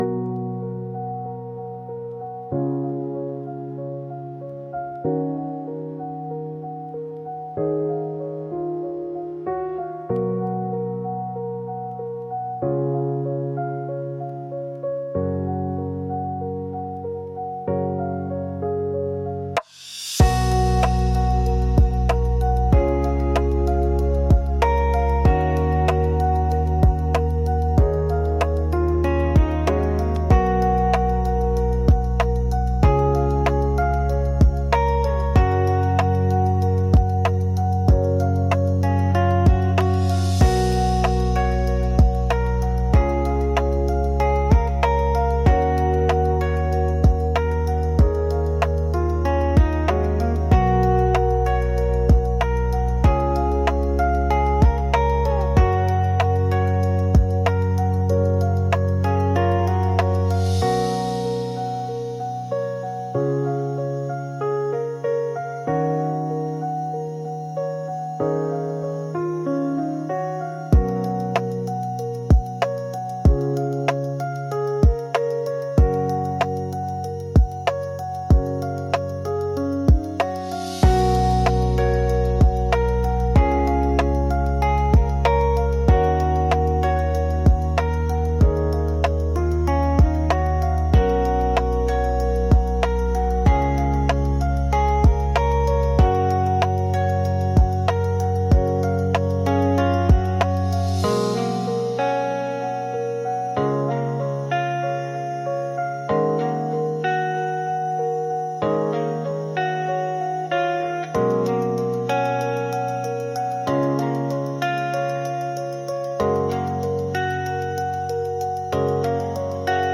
F# Major – 95 BPM
Hip-hop
Lofi
Pop
Rnb